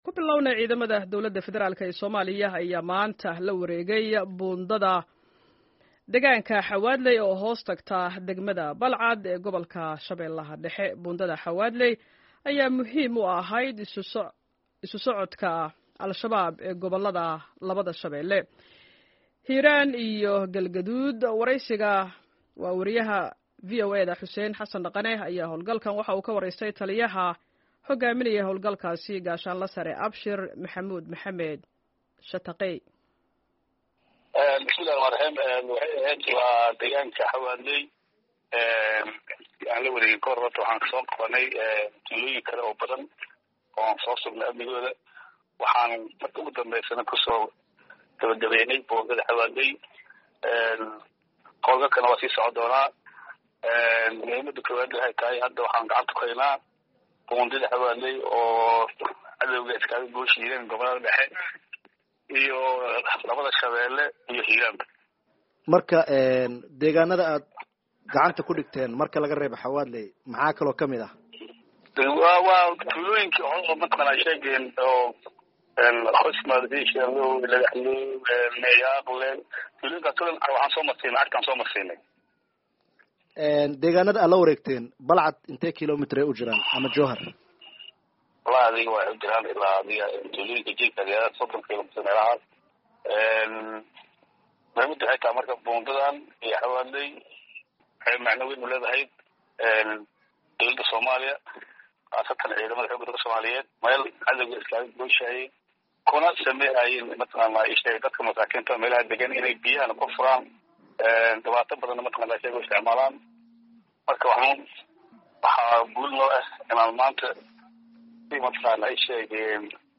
Wareysi: Ciidamada dowladda oo Al-Shabaab kala wareegay degaanno muhiim ah